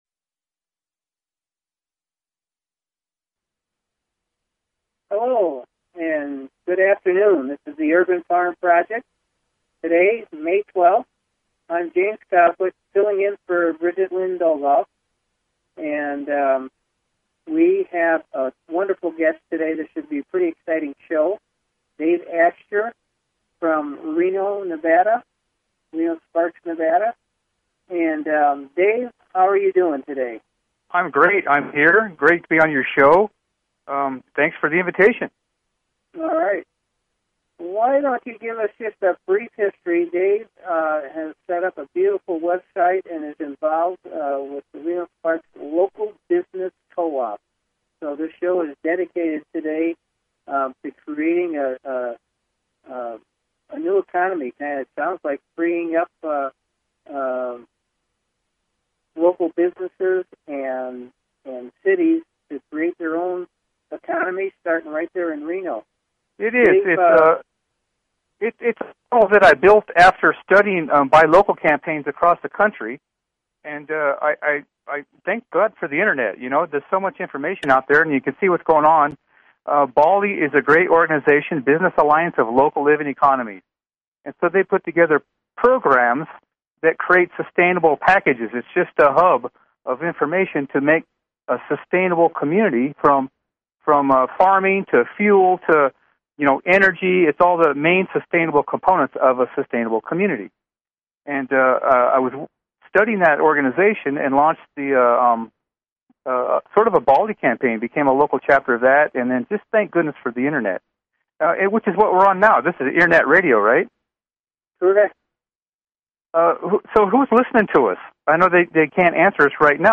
Talk Show Episode, Audio Podcast, The_Urban_Farm_Project and Courtesy of BBS Radio on , show guests , about , categorized as